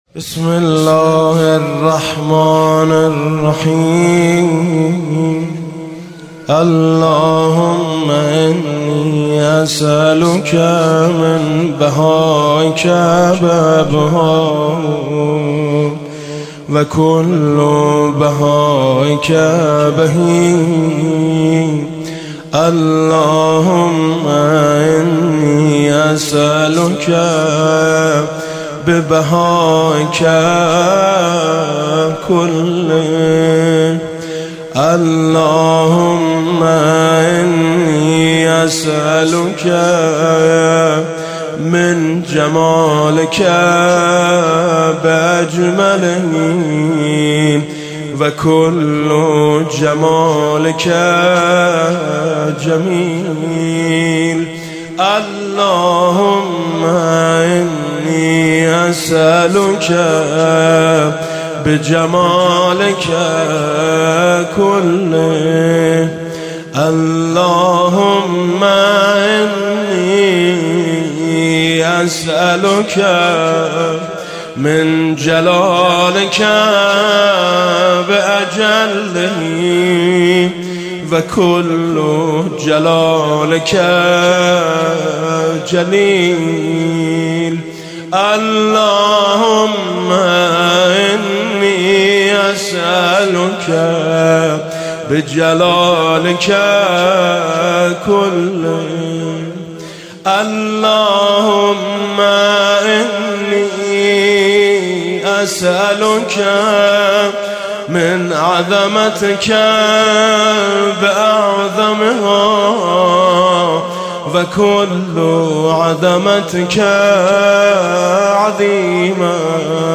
صوت/ "دعای سحر" با نوای میثم مطیعی | مجاهدت